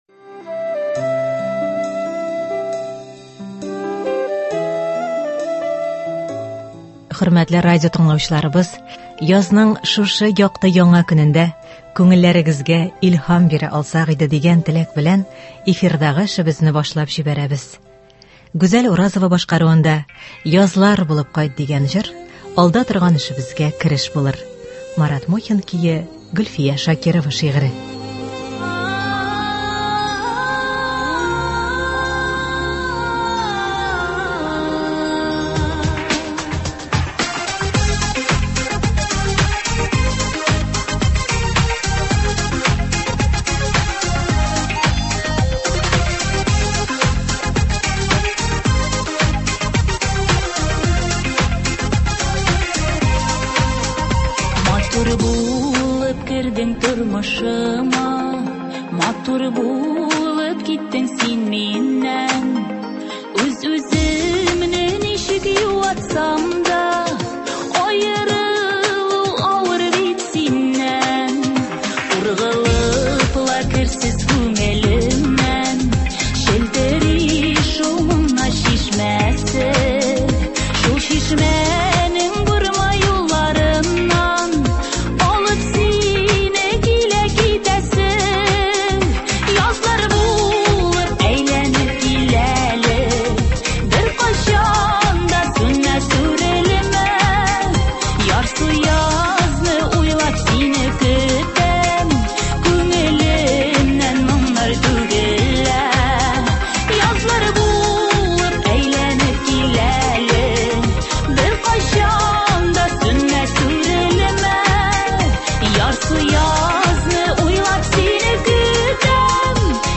«Бишек җыры». Әдәби-музыкаль композиция.